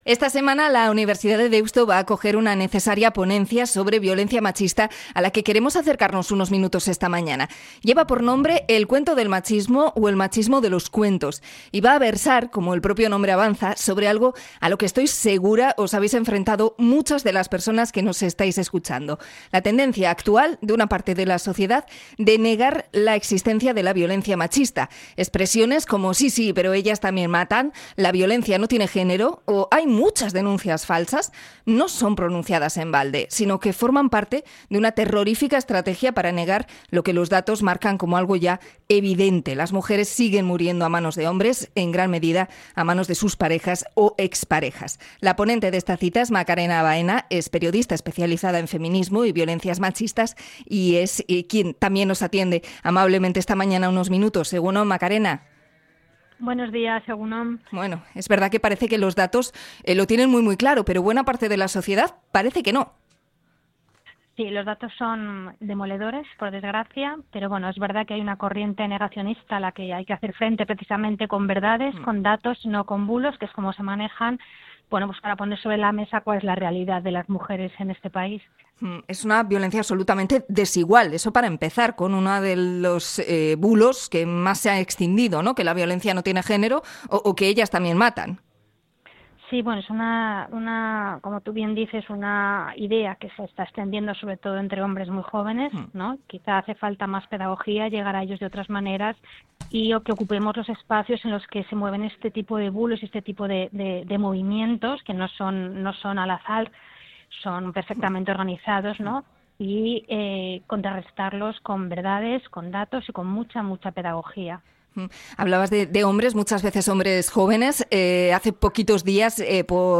La conversación se ha emitido en el programa EgunON Magazine.